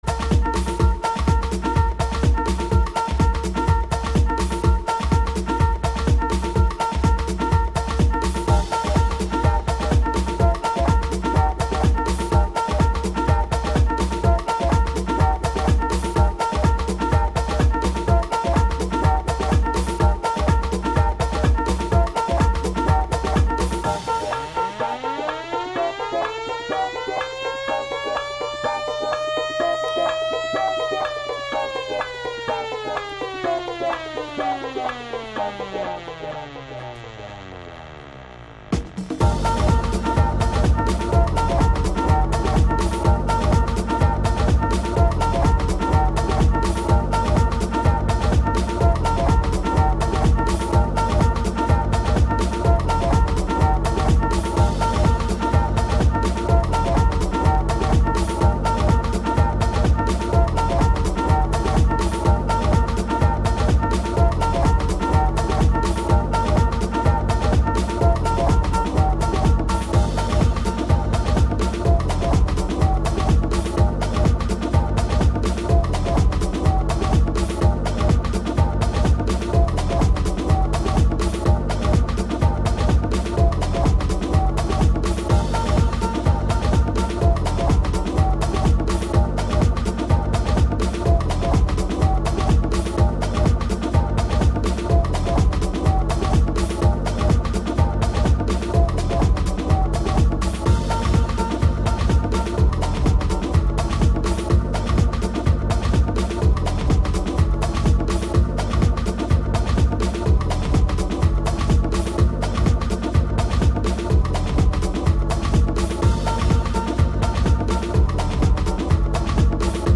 Funk Soul